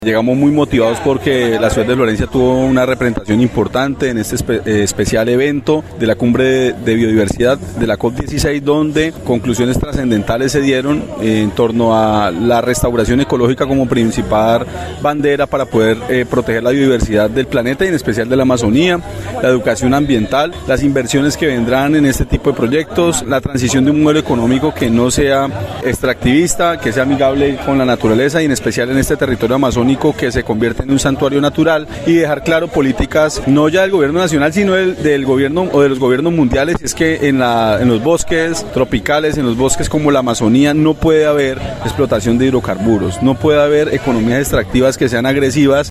Así lo dio a conocer el alcalde de la ciudad Monsalve Ascanio, quien dijo que, para proteger la biodiversidad es necesario trabajar en la restauración ecológica junto a una educación ambiental, mismos que traerán inversión financiera para la ciudad.
ALCALDE_MONSALVE_ASCANIO_TEMAS_-_copia.mp3